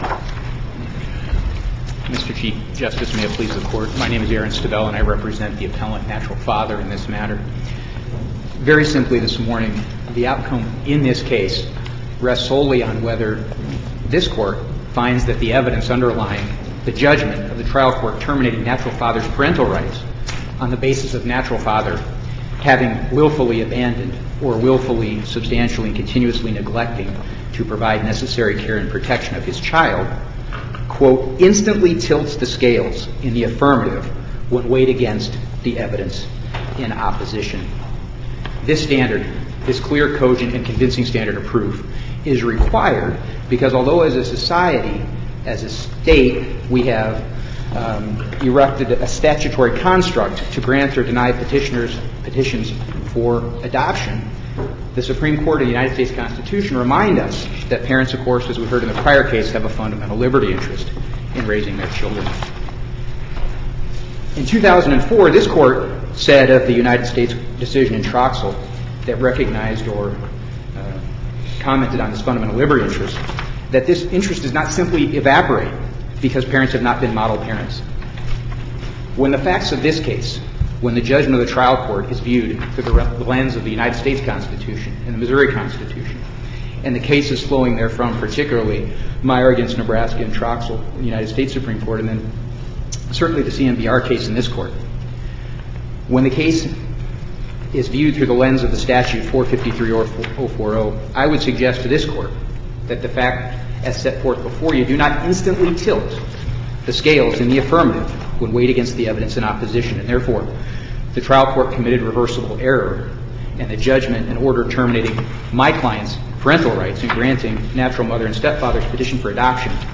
MP3 audio file of arguments in SC96376